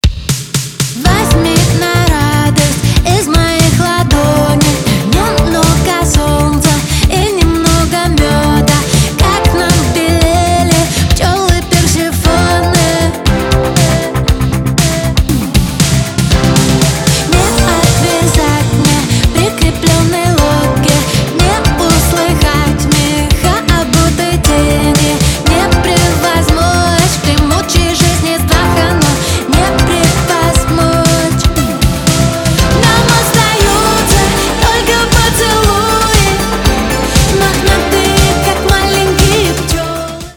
Поп Музыка
кавер